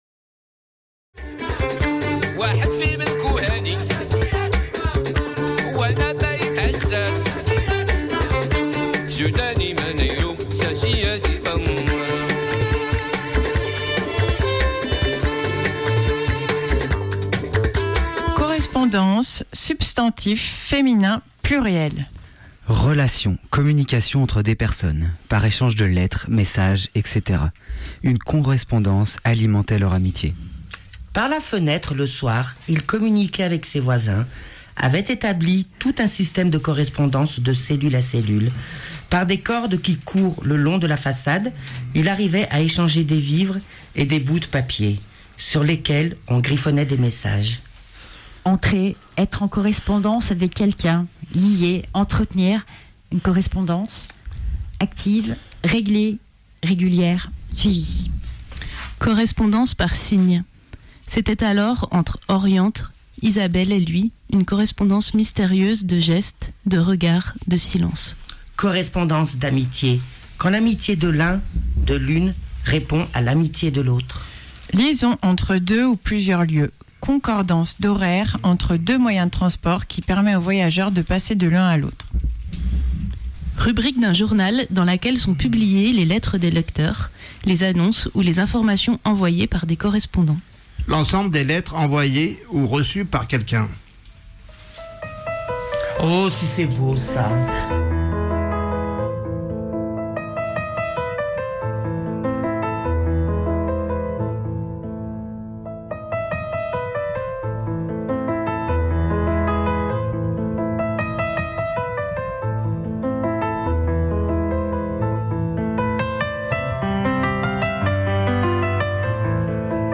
CAARUD Ruptures et le CHRS APUS en direct sur le 102.2, jeudi 6 juin à 15h ! - Radio Canut
Dans le cadre d'un projet radio entre le CHRS APUS, TAPAJ et le CAARUD Ruptures, nous avons réalisé de nombreuses prises de sons, coup de gueule, lectures, chansons et bien plus encore.
Vous pourrez entendre des chansons exclusives mais aussi des ateliers basages en direct et des prises de position.